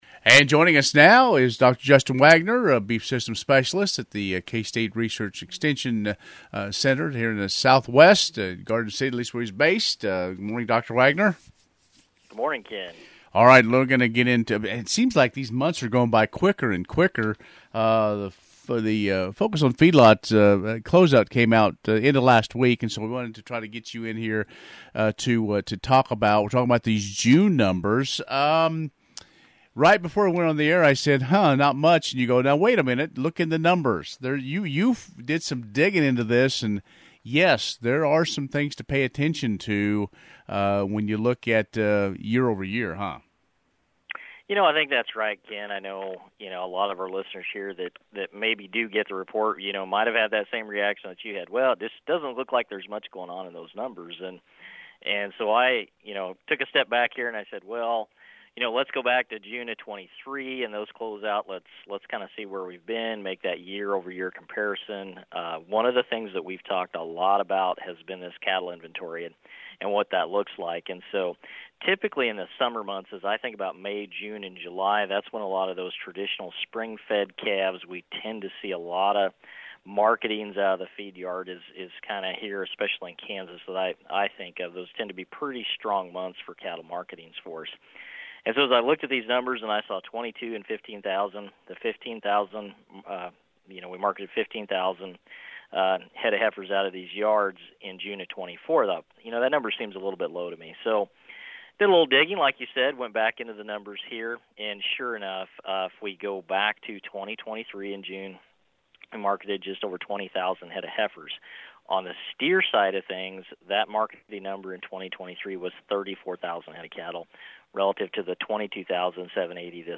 Focus on Feedlots Interview